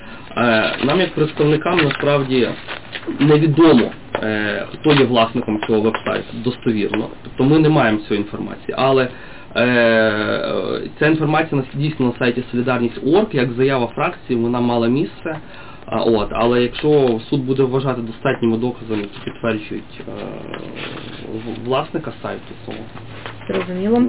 "Апофеозом процесса" он назвал заявление представителей БПП, что они не знают, кому принадлежит официальный сайт БПП. Оно было сделано под аудиофиксацию